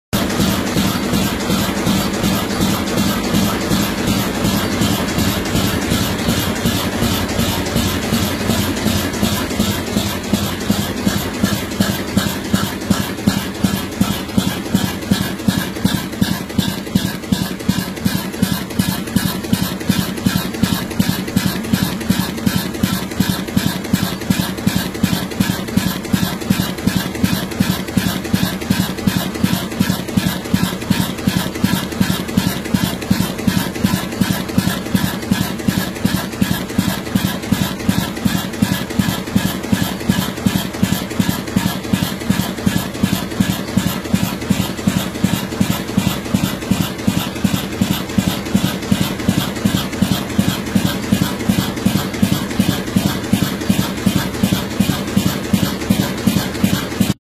Dieselmotor “Oude Bram”.
Benieuwd naar het geluid van deze prachtige motor.
De motor is een twee cilinder viertakt dieselmotor met een vermogen van maximaal 60  APK (44KW) bij 360 omwentelingen per minuut.
Geluid-van-de-Oude-Bram-in-werking..mp3